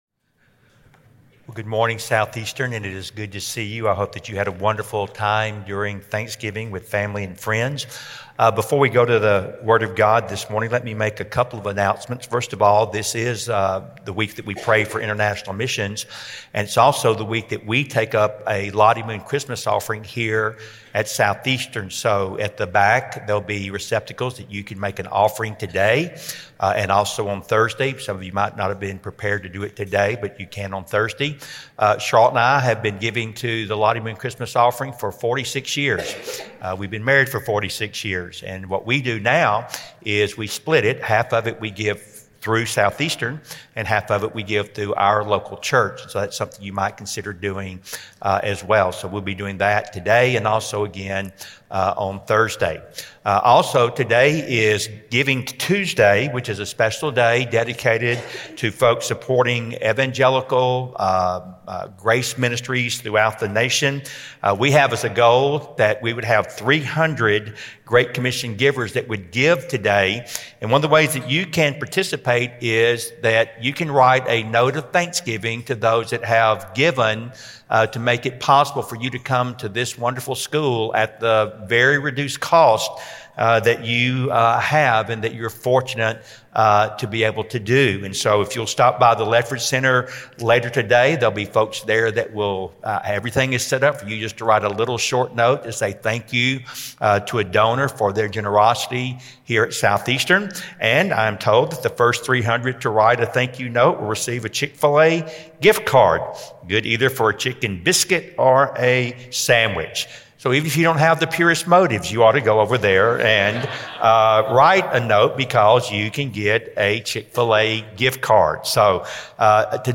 Southeastern Baptist Theological Seminary's Podcast consists of chapel and conference messages, devotionals and promotional information in both audio and video format.